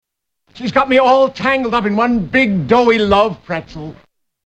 Tags: 3rd Rock from the Sun TV sitcom Dick Solomon John Lithgow Dick Solomon clips